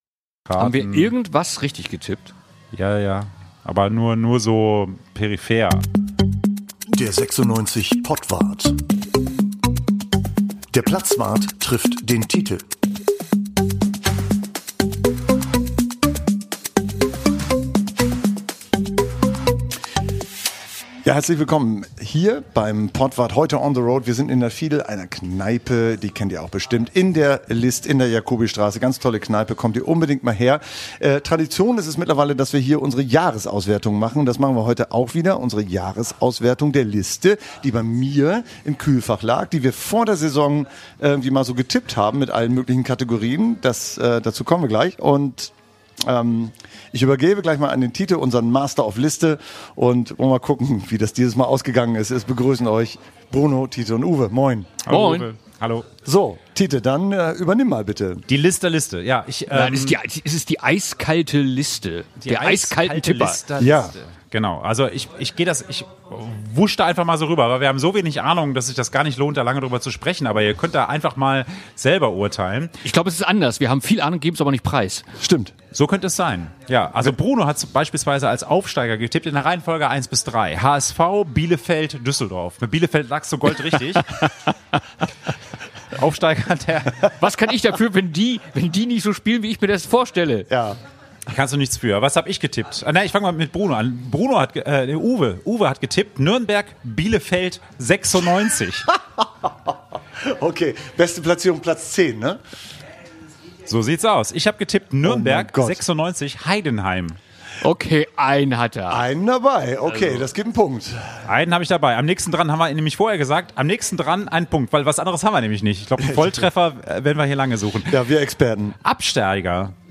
Zum Saisonabschluss dieses Mal in der Kneipe „Fiedel“ in der List. Wir blicken auf die Eisfach-Papers, unsere Tipps, die wir zu Beginn der Saison abgegeben haben.